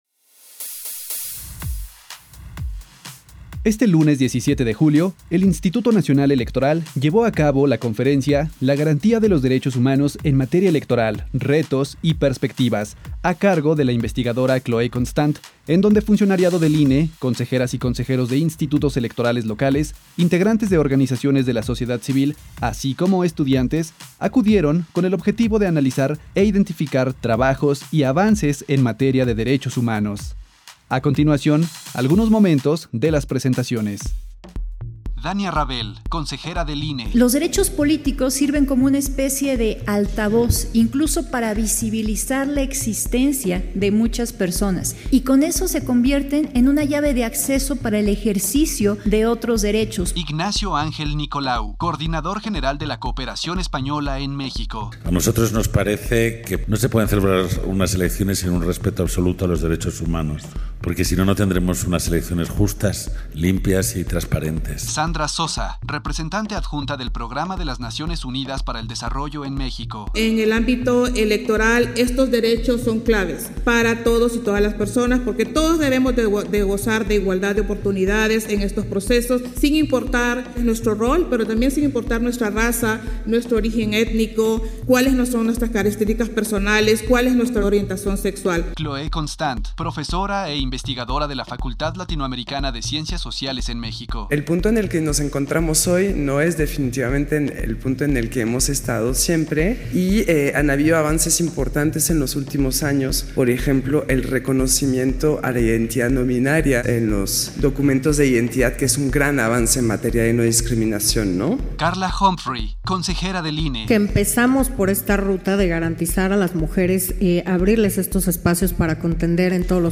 PD_1491-MEZCLA_-CONFERENCIA-GARANTIA-DE-LOS-DERECHOS-HUMANOS-EN-MATERIA-ELECTORAL_-17-julio-2023